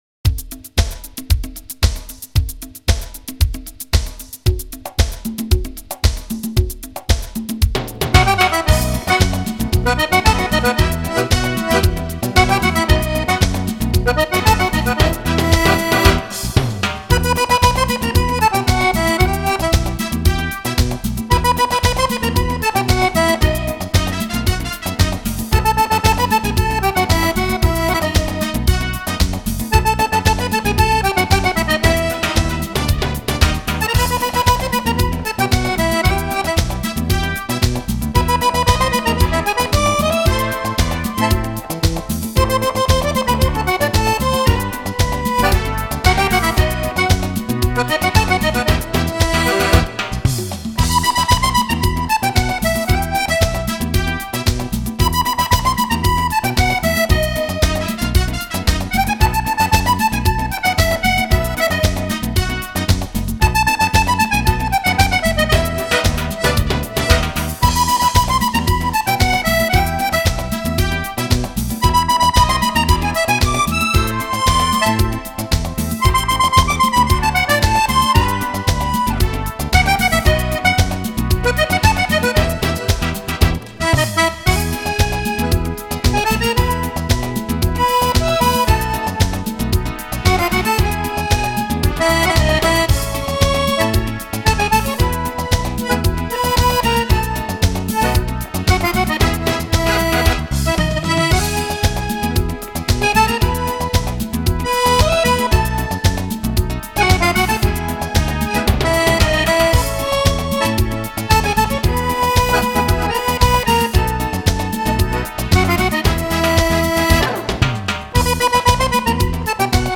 Samba
ballabili per fisarmonica e orchestra